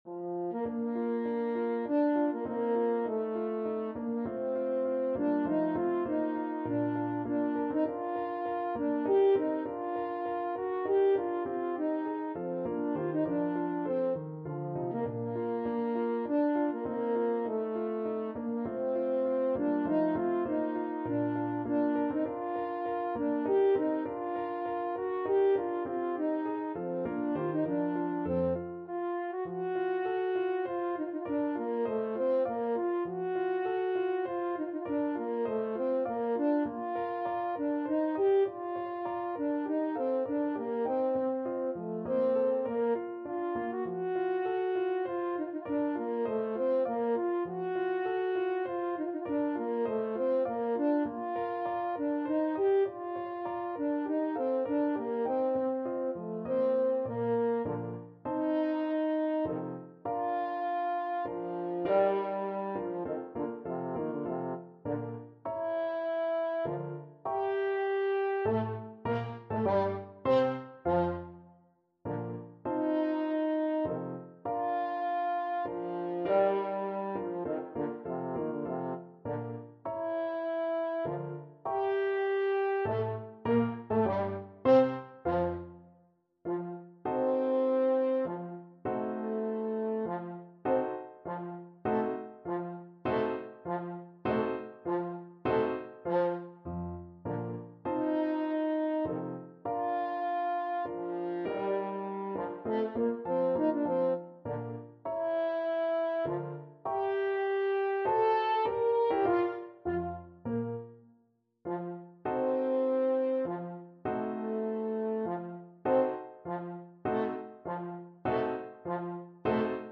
3/4 (View more 3/4 Music)
Menuetto Moderato e grazioso
Classical (View more Classical French Horn Music)